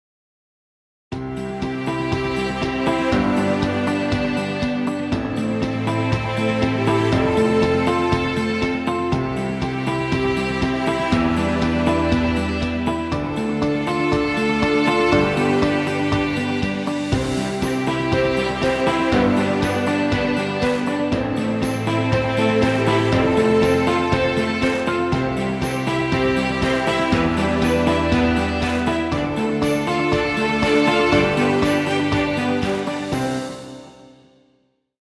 festive corporate track with positive bright mood.